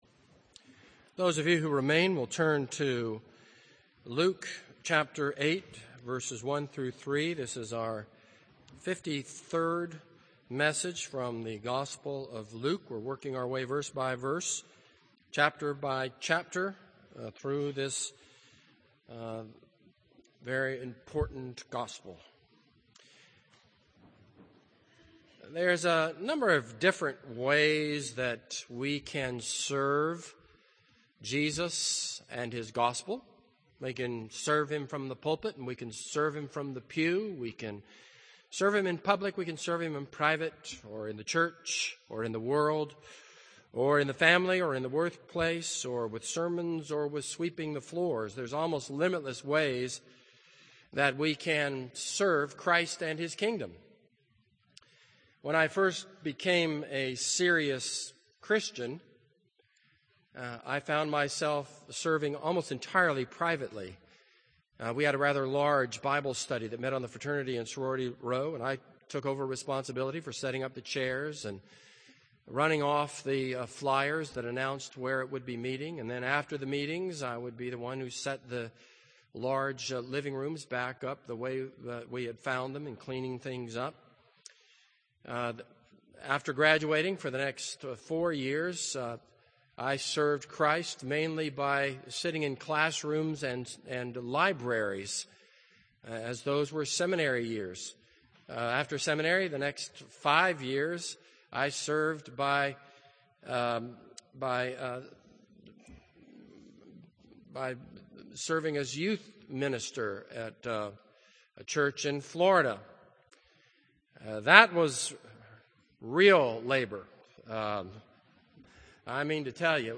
This is a sermon on Luke 8:1-3.